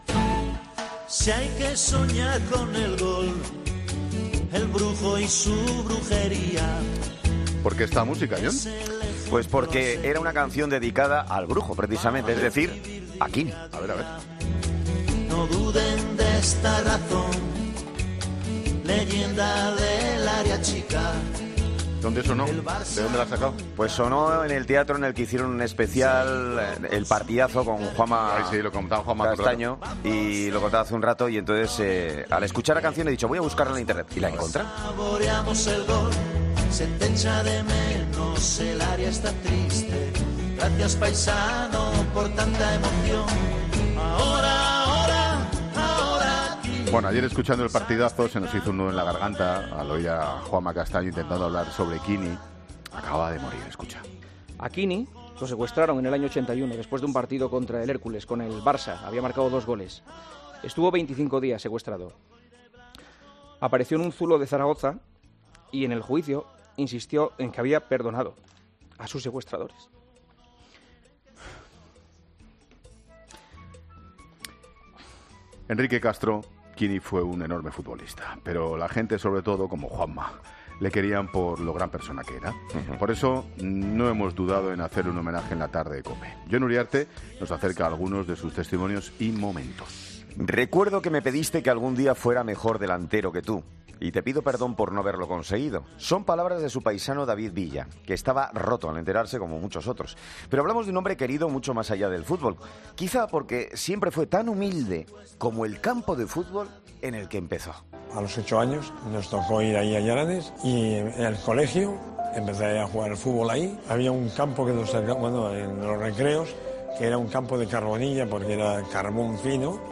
AUDIO: Sonidos de recuerdos de quiénes le conocieron, de sus goles y de su despedida del fútbol.